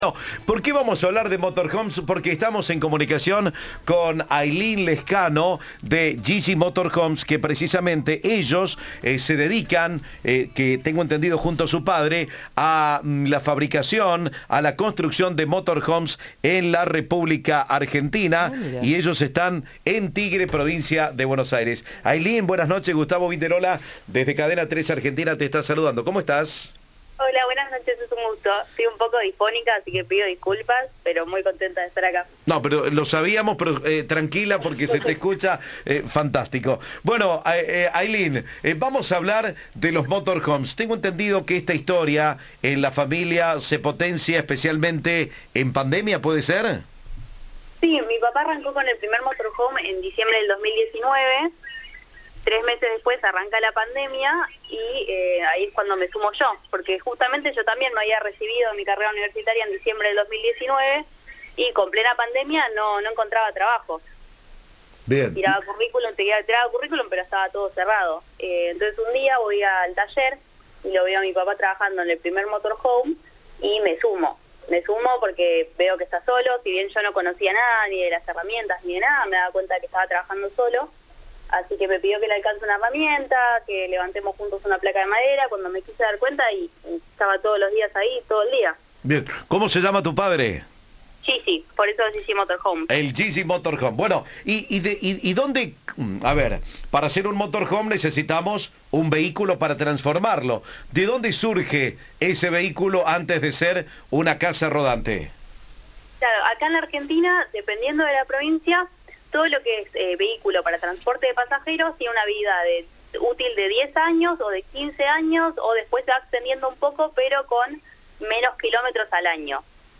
Entrevista de "Turno Noche".